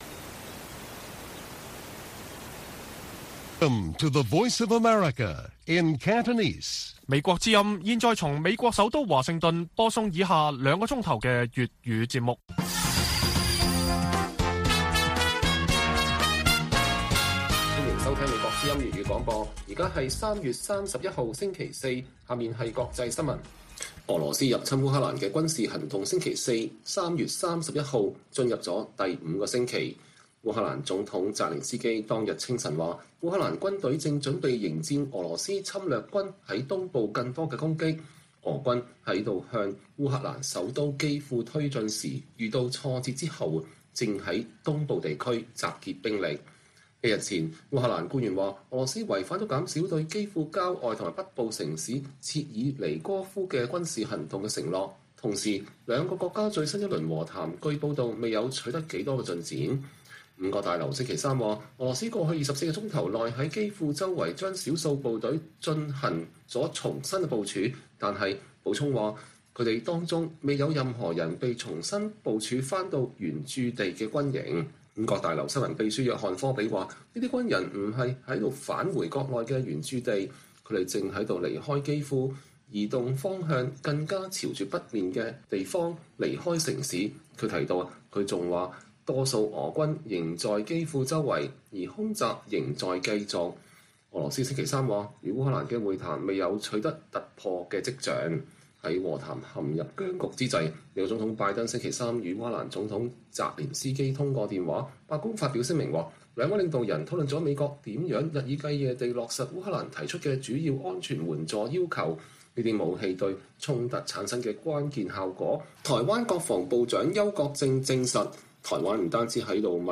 粵語新聞 晚上9-10點： 澤連斯基說烏軍準備迎戰俄軍在東部更多的攻擊